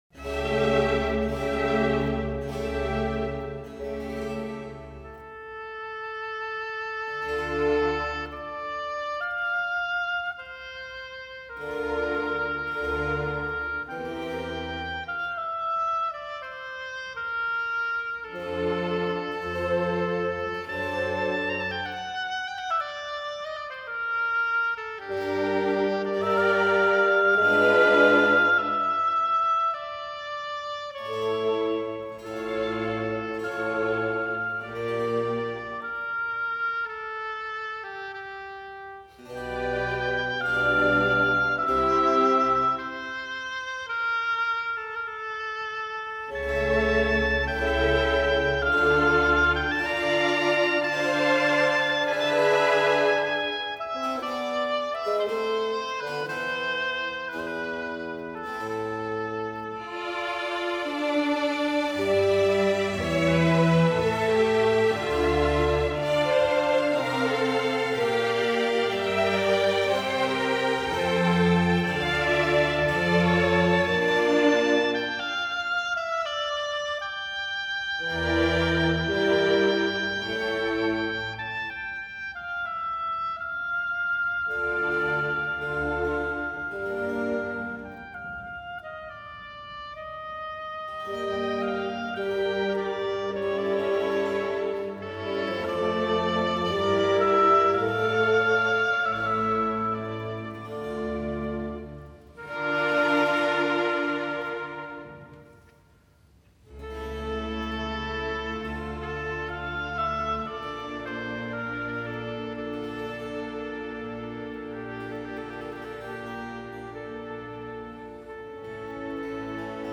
柔板与断奏
双簧管和弦乐组的协奏乐章，双簧管的旋律如溪流汩汩流动，而弦乐缓缓而行，在沉思和冥想中也呈现出淡淡的忧虑。